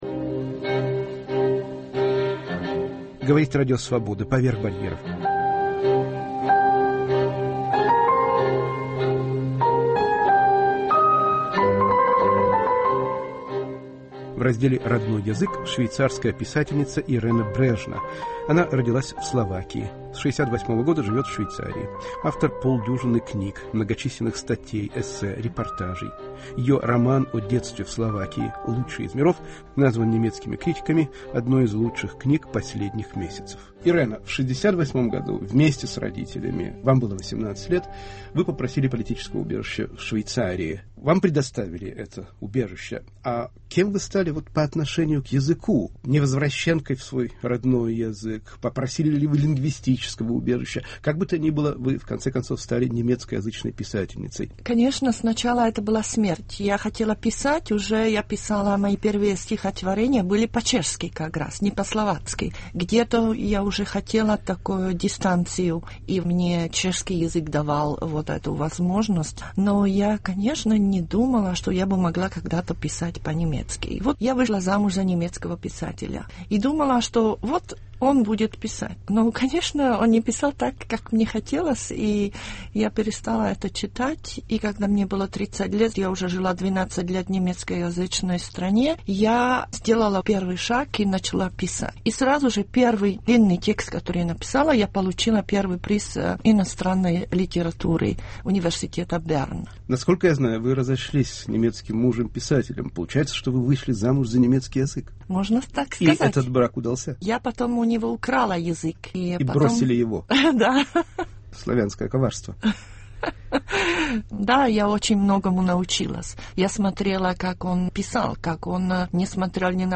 "Родной язык": беседа с швейцарской писательницей словацкого происхождения Иреной Брежной